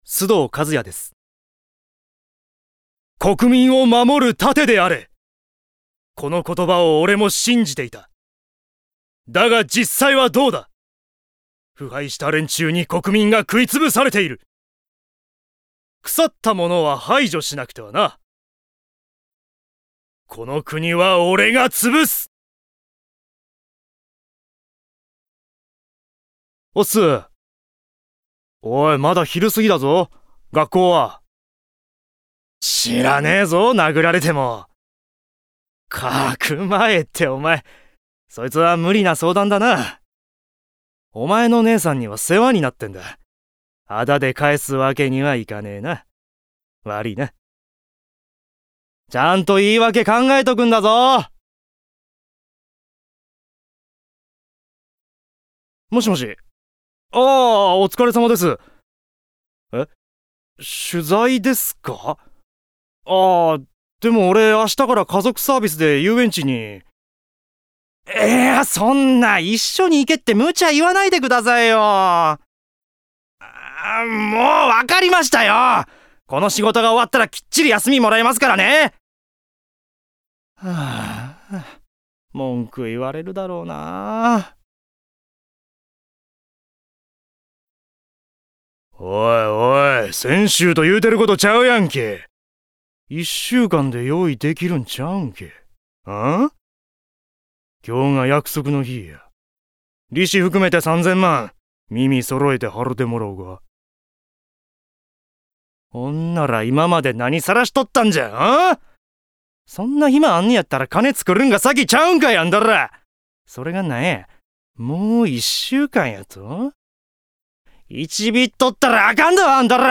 VOICE SAMPLE